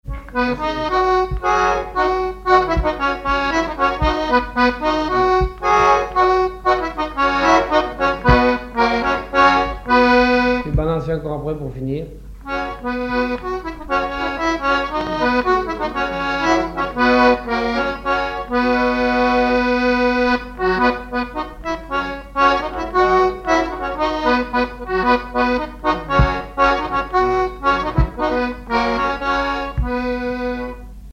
Saint-Hilaire-de-Riez
danse : quadrille : pastourelle
accordéon diatonique
Pièce musicale inédite